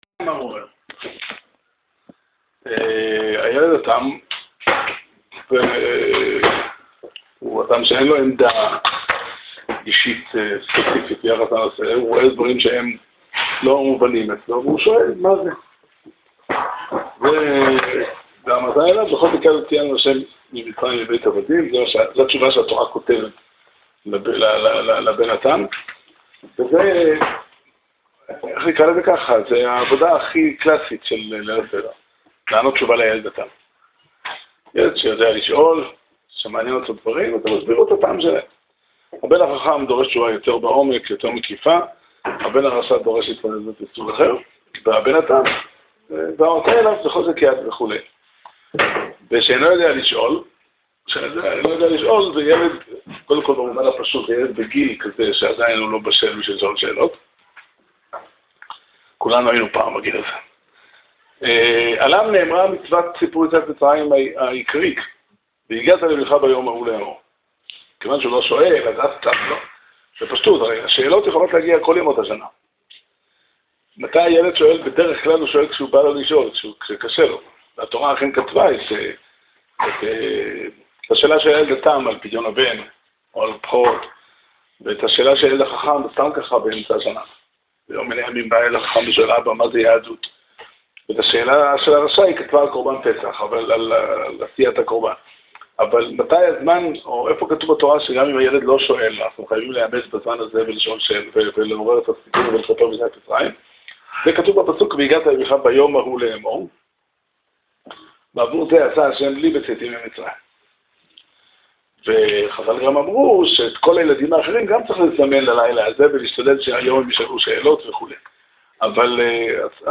שיעור שנמסר בבית המדרש 'פתחי עולם' בתאריך כ"ז אדר תשע"ח